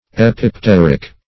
Search Result for " epipteric" : The Collaborative International Dictionary of English v.0.48: Epipteric \Ep`ip*ter"ic\, a. [Pref. epi- + Gr.